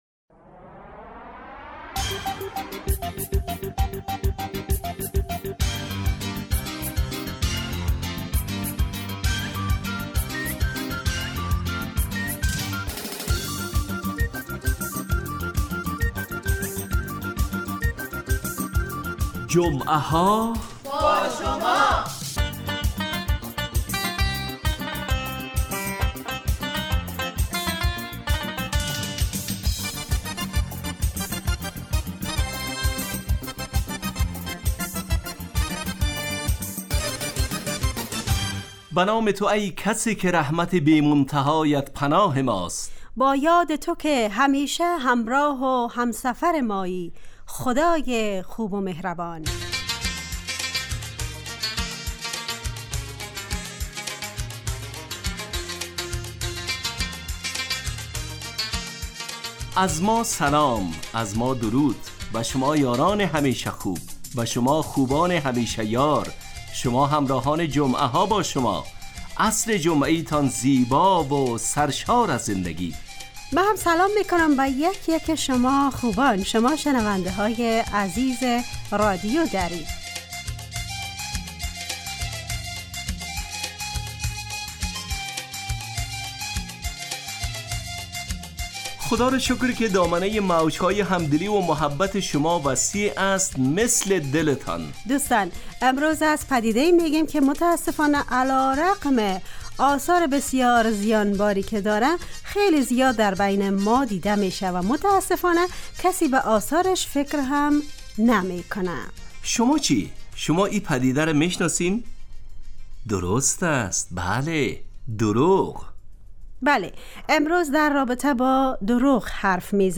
جمعه ها باشما برنامه ایست ترکیبی نمایشی که عصرهای جمعه بمدت 40 دقیقه در ساعت 17:15 دقیقه به وقت افغانستان پخش می شود و هرهفته به یکی از موضوعات اجتماعی...